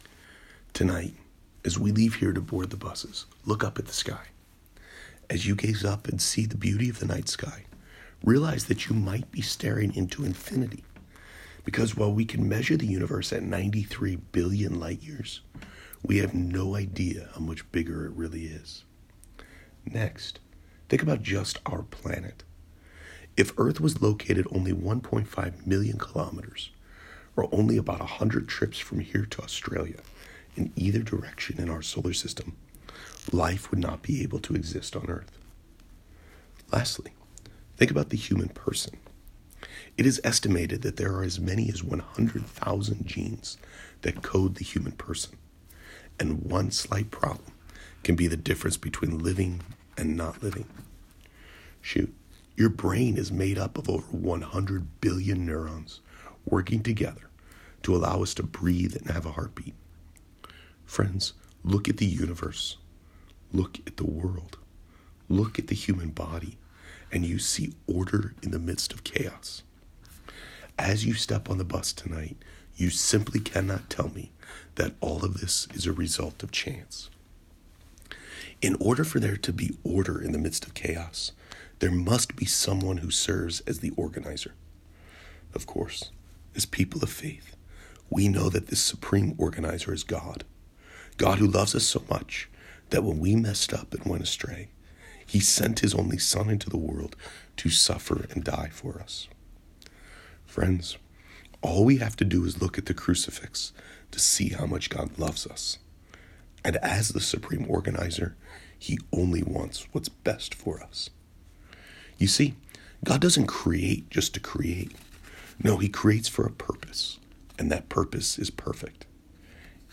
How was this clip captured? Pro-Life Send Off Youth Mass